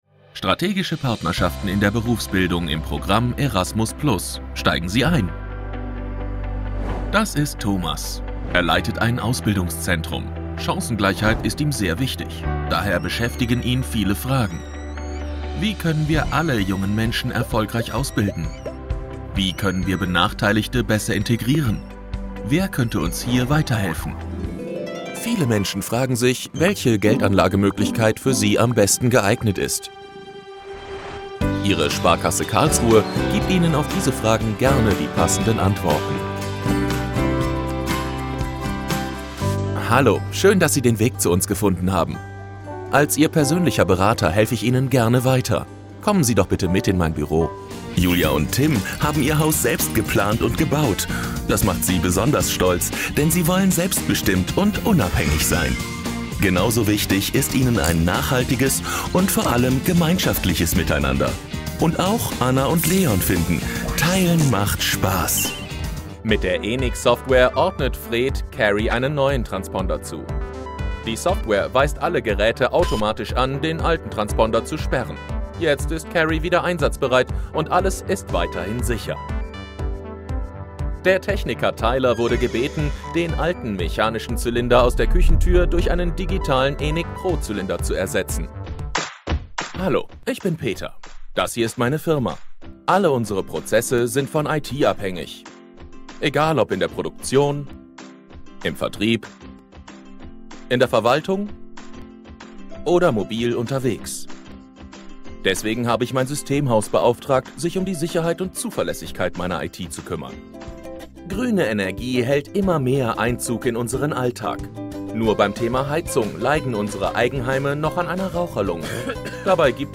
Explainer